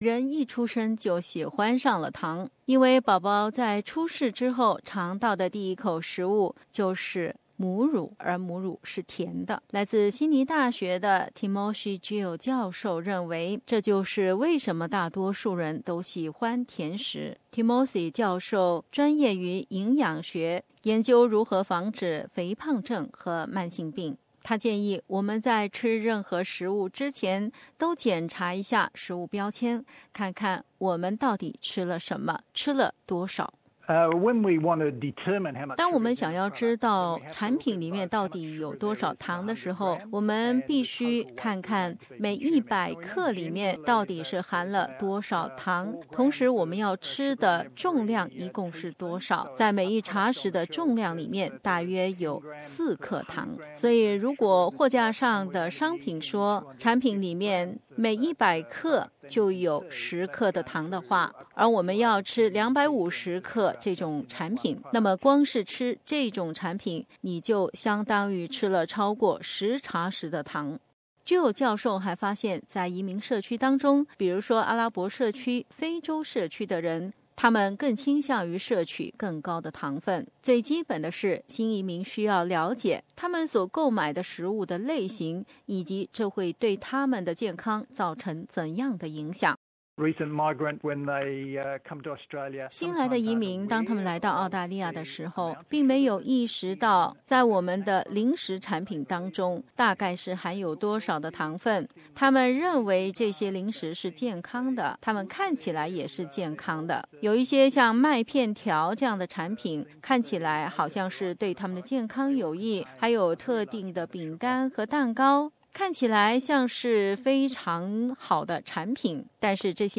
下面我们来听听本台对健康专家的采访，回答以上的问题： READ MORE 坏食物怎样在大脑发生垃圾效应 澳洲原住民有哪些独特的甜食？